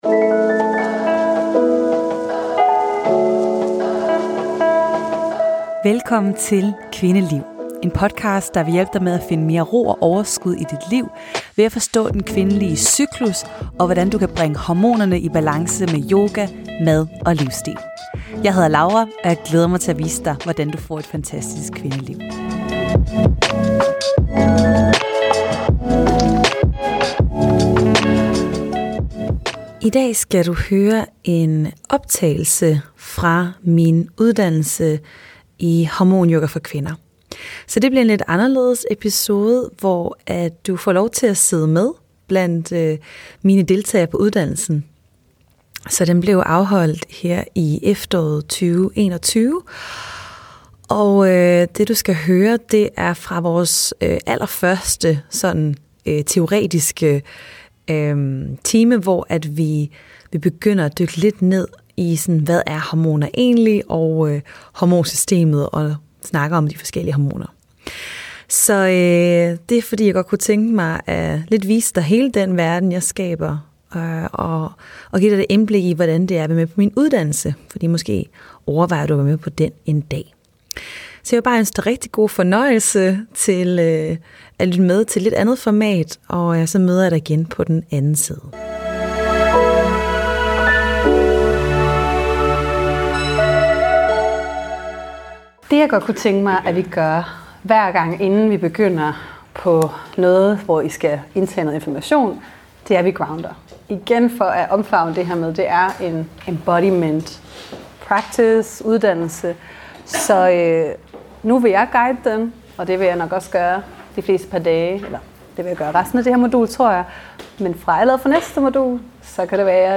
Optagelsen er fra vores første teoretiske workshop om hormonsystemets grundprincipper, hvor vi dykker ned i det kvindelige hormonsystem og hvert enkelt hormon, som er relevant for os kvinder.